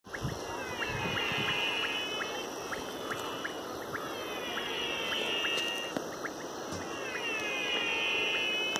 There are frogs that honestly sound like octopus suction cups attaching and detaching, and other frogs that sound like they’re saying mlaaagaaaa (audio below).